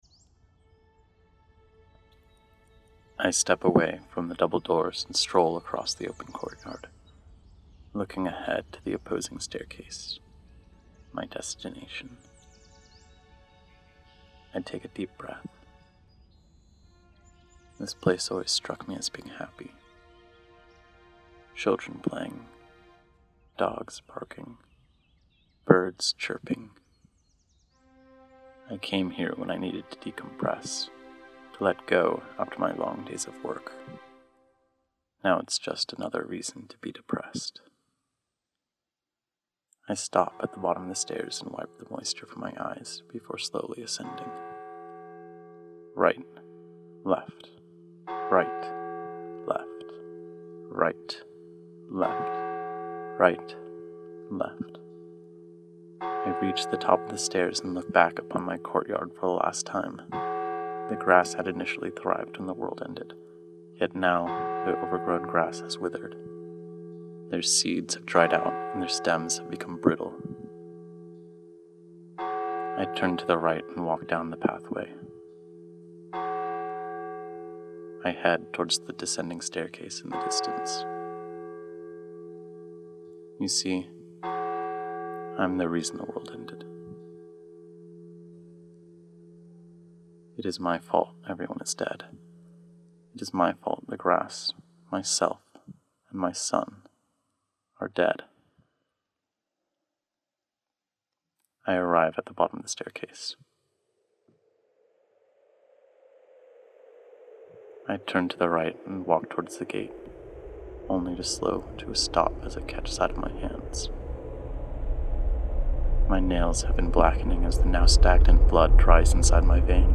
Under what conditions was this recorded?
This audio follows Judas' father in the days before his suicide. Best with actual headphones rather than earbuds.